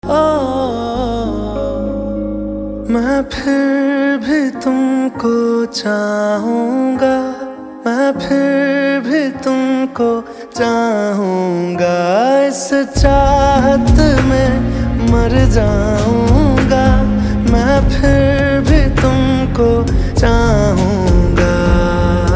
Iconic Bollywood unconditional-love melody hook